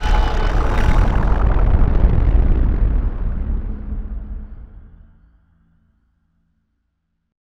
gearuparmor.wav